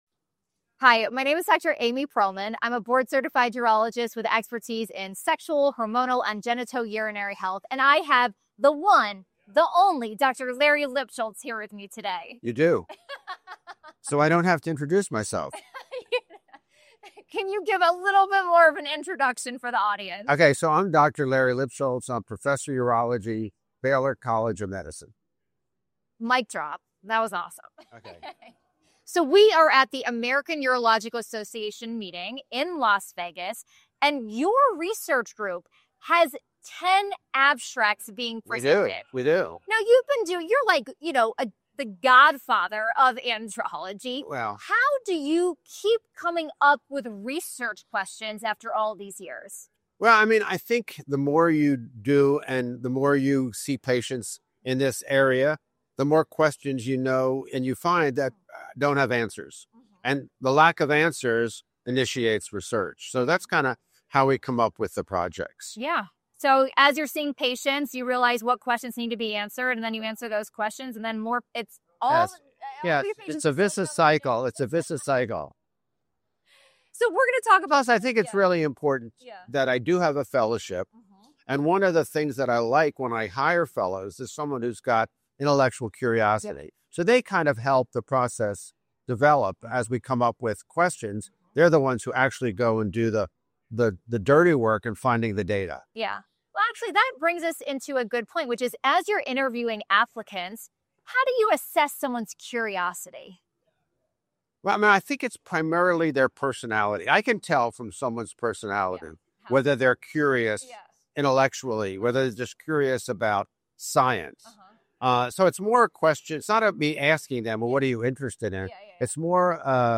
Recorded at the American Urological Association's (AUA) 2025 Annual Meeting in Las Vegas, NV, this conversation explores the questions that continue to drive one of urology’s most prolific clinician-researchers—and the answers his team is working to uncover.